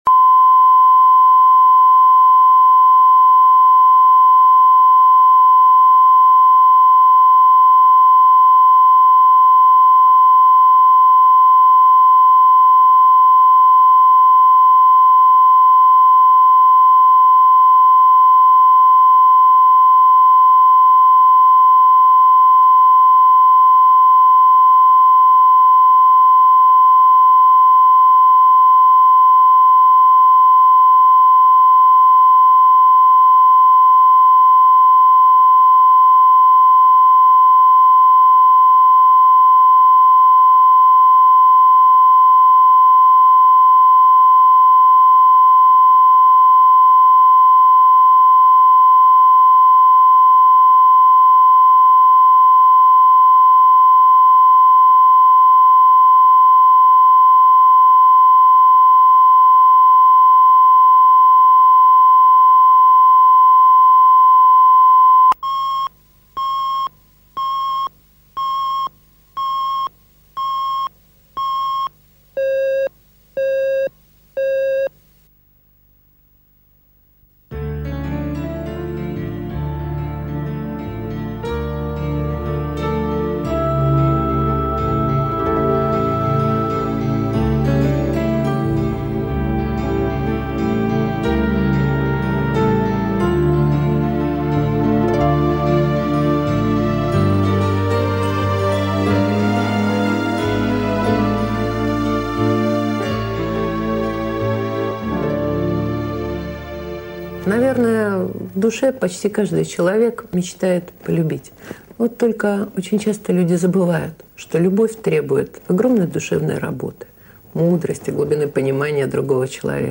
Аудиокнига Дурнушка